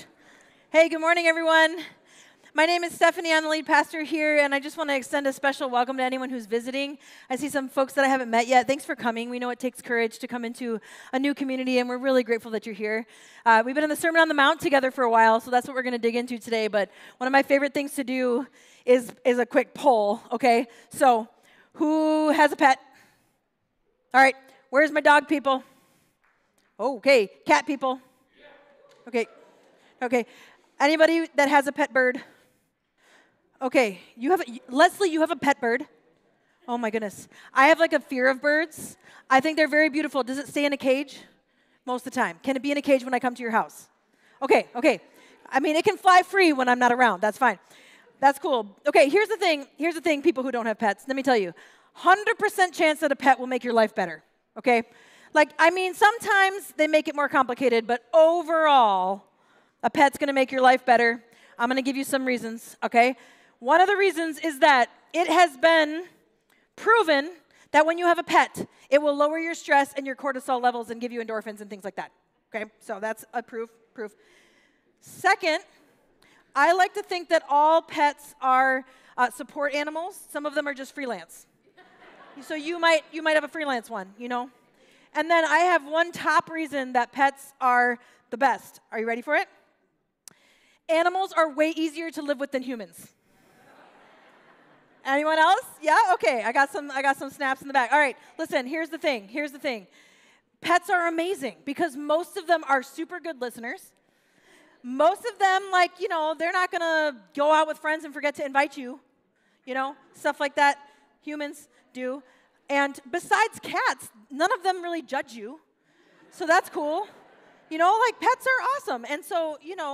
Mill City Church Sermons The Road to Life Planks, Pearls and Pigs Feb 03 2025 | 00:37:46 1x 00:00 / 00:37:46 Subscribe Share RSS Feed Share Link Embed